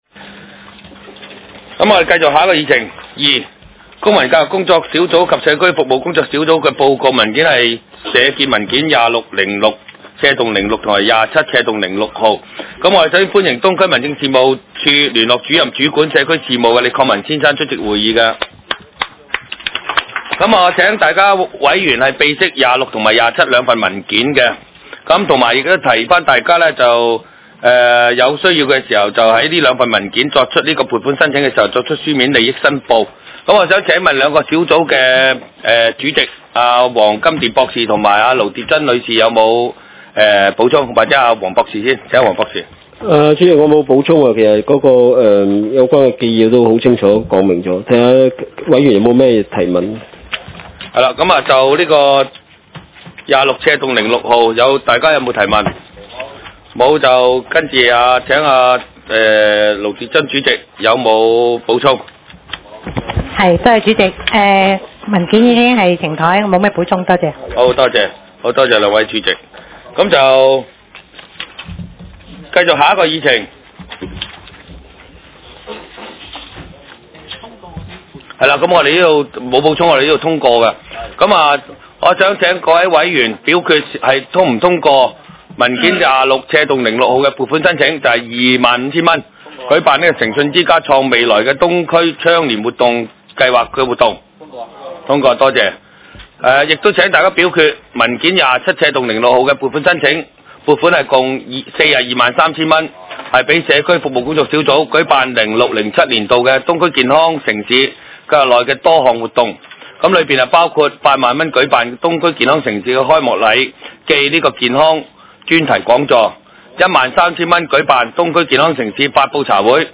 社區建設及服務委員會第四次會議
東區法院大樓11樓東區議會會議室